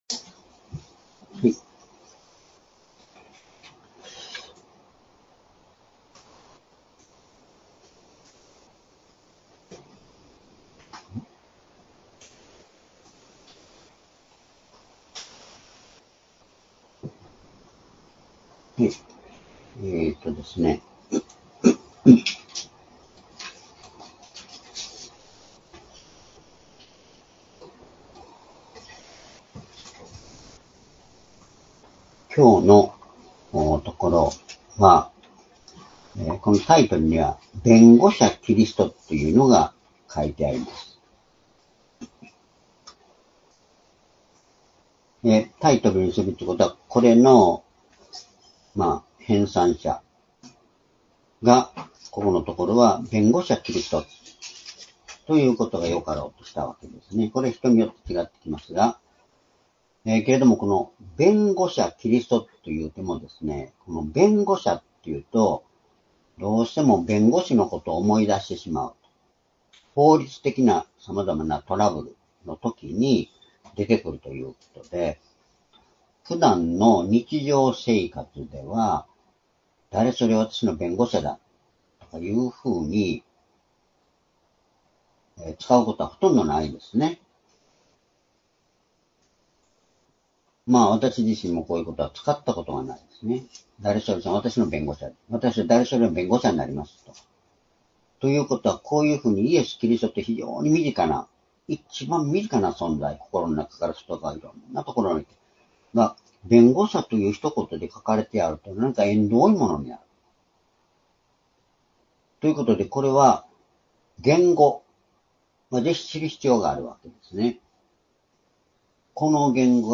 主日礼拝日時 ２０２５年７月１３日（主日礼拝） 聖書講話箇所 「イエス・キリスト、罪のあがない、神の愛」 Ⅰヨハネ２章１節-５節 ※視聴できない場合は をクリックしてください。